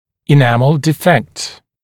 [ɪ’næml dɪ’fekt] [‘diːfekt] [и’нэмл ди’фэкт] [‘ди:фэкт] дефект эмали